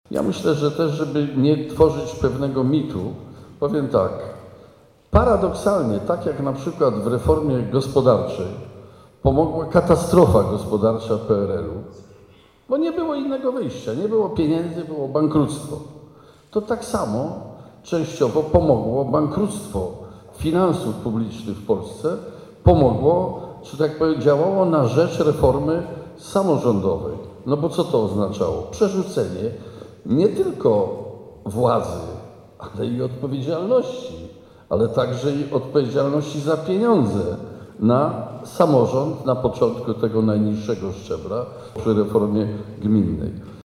Blisko 3 godziny trwała debata jaką zorganizowano w magistracie w Bielsku-Białej.